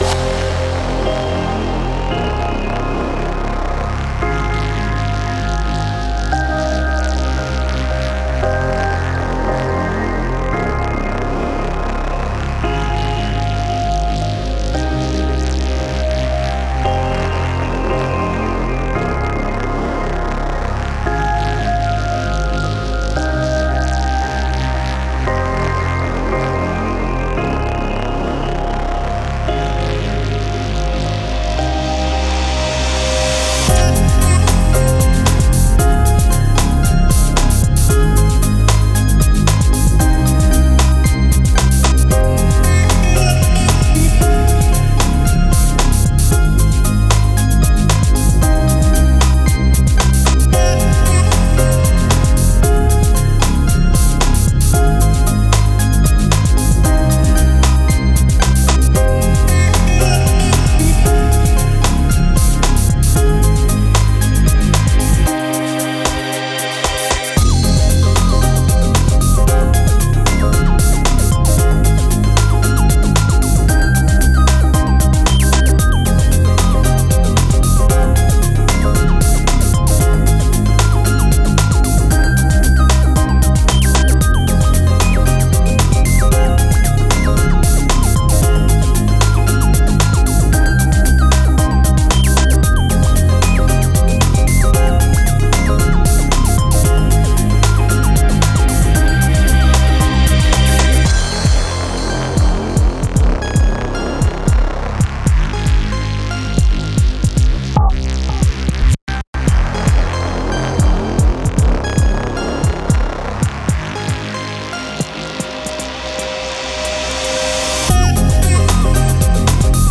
Hallo, ich hab so rumgespielt mit meiner DAW und habe aus einer früher eingespielten Chord Progression eine Idee etwas ausgebaut.
FX fehlt auch noch einiges. Die Drums sind auch noch variierbar das es immer die gleiche Loop ist.
Ist ne ruhige Nummer.